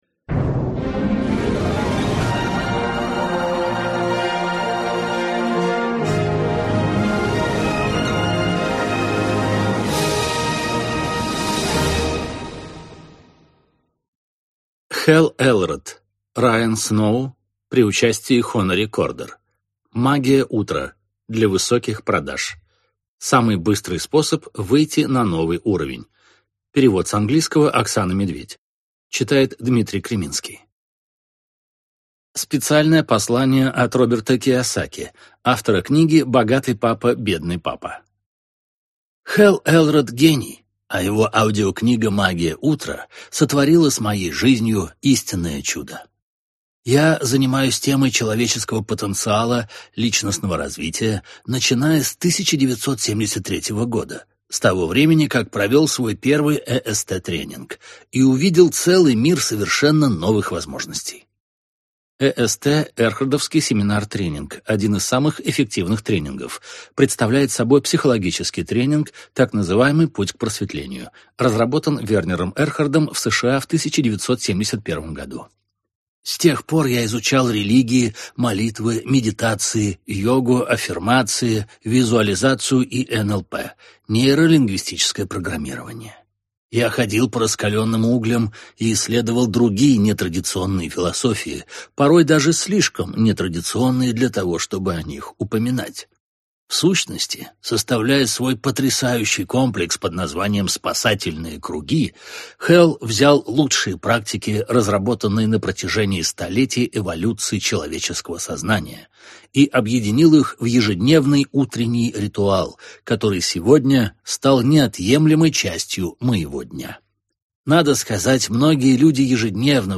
Аудиокнига Магия утра для высоких продаж | Библиотека аудиокниг